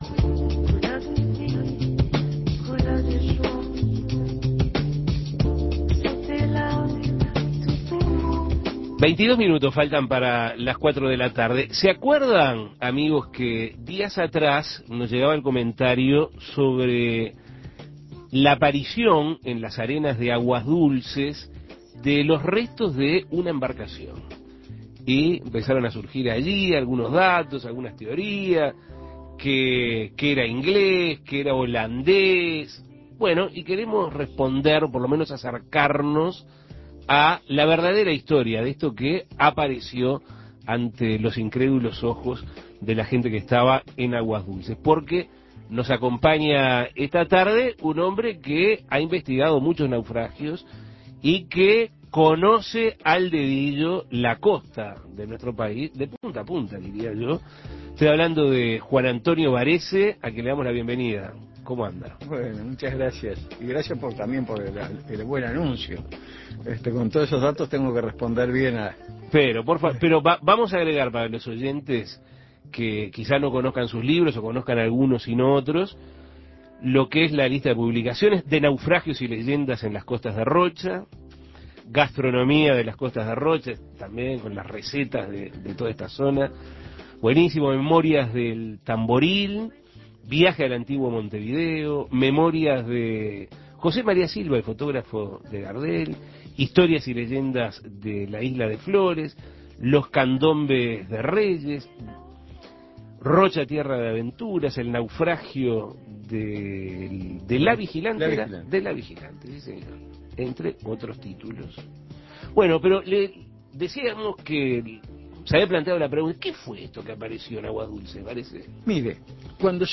Recientemente aparecieron en las arenas de Aguas Dulces los restos de una embarcación. A partir de este hecho, comenzaron a surgir diversos datos y teorías sobre el descubrimiento. Para poder acercar a los oyentes a la verdadera historia de estos restos, en Asuntos Pendientes se entrevistó